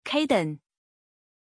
Pronunciation of Caden
pronunciation-caden-zh.mp3